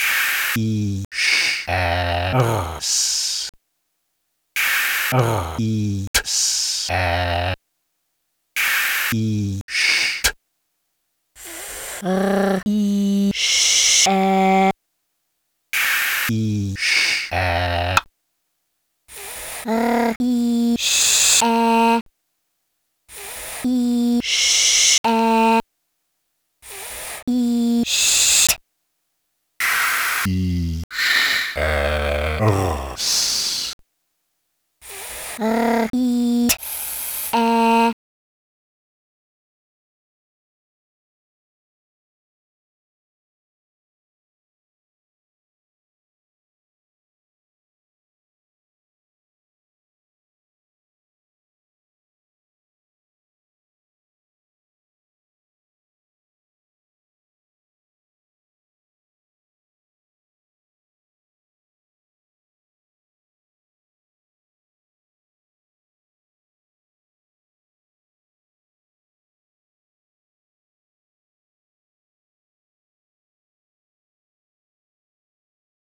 phonetic typewriter angeln
sehr schön gelungener zugenbrecher-abkömmling, an dem sich unser kleines sprechprogramm tüchtig abarbeiten kann. ich bin für diesen beitrag sehr dankbar, denn dieses sprechtraining für sprachmaschinen bringt zwei konzeptionelle anliegen des phont-projekts unmittelbar zur anschauung: die möglichkeit, zugleich analytisch und synthetisch mit sprachmaterial umzugehen - es zu untersuchen und zugleich zu gestalten. und dann die gelegenheit, mit dieser technisch-artistischen krückenkonstruktion bedeutungsvolle und zugleich ästhetsch reizvolle aussagen her zu stellen. besten dank auch für die humorvolle ironie dieses beitrags.